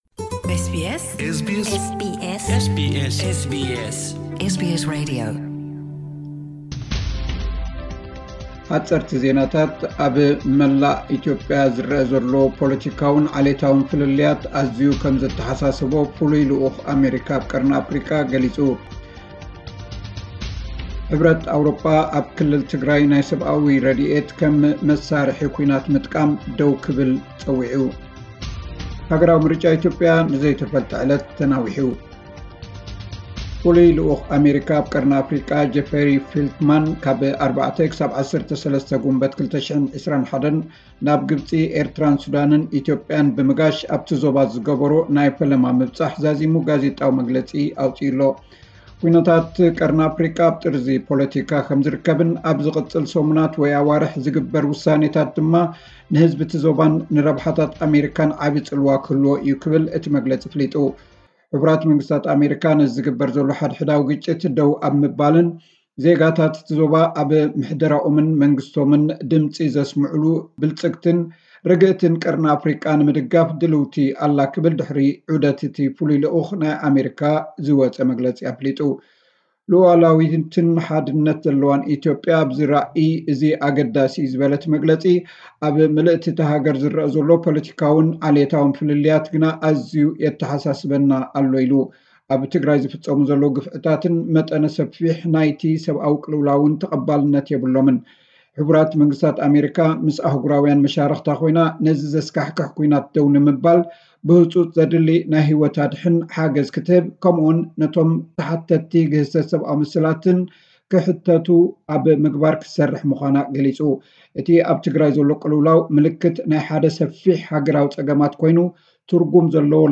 ሓጸርቲ ጸብጻባት፥ ኣብ ምልእ ኢትዮጵያ ዝረአ ዘሎ ፖለቲካውን ዓሌታውን ፍልልያት ኣዝዩ ከምዘተሓሳስቦ ፍሉይ ልኡኽ ኣመሪካ ኣብ ቀርኒ ኣፍሪቃ ገሊጹ። ሕብረት አውሮጳ ኣብ ክልል ትግራይ ናይ ሰብኣዊ ረዲኤት ከም መሳርሒ ኲናት ምጥቃም ደው ከብል ጸዊዑ። ሃገራዊ ምርጫ ኢትዮጵያ ንዘይተፈልጠ ዕለት ተናዊሑ።